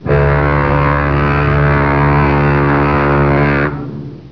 Large Ship Horns
Large ships air horn with echo (Naval or Merchant marine) - Large ships air horn (Large Tug boat) -Large Ships Air Horn